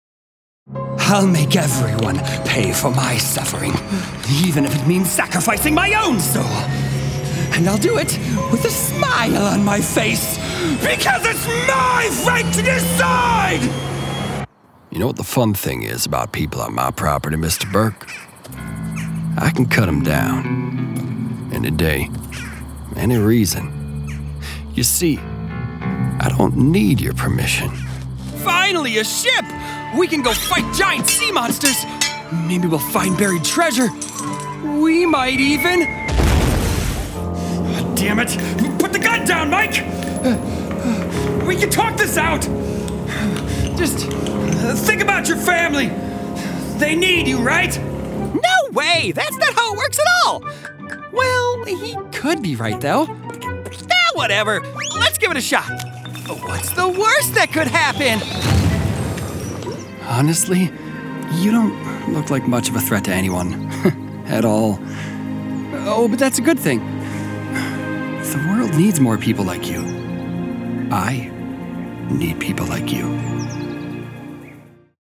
Natural, Llamativo, Cool, Amable, Cálida
He is often described as warm and charismatic, with a clear, articulate delivery that conveys both sincerity and a playful sense of humor.